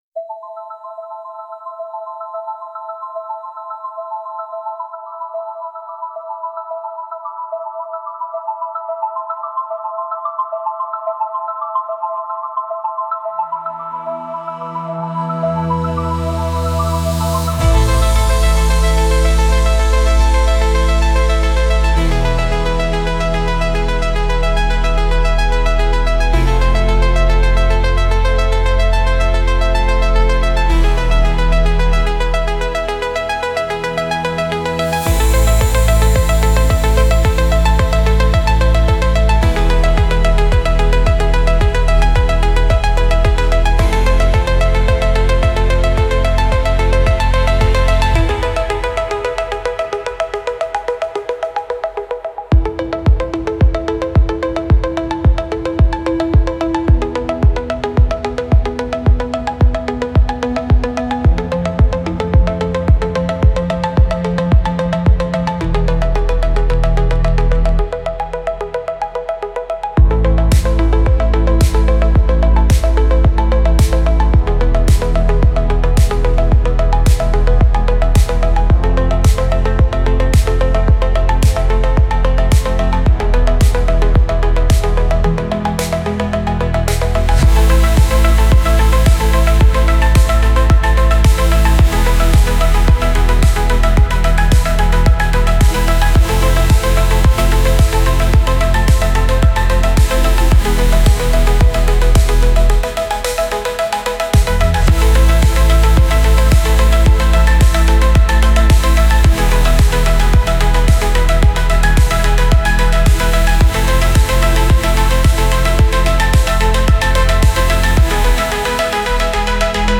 Genre: Cinematic / Epic Music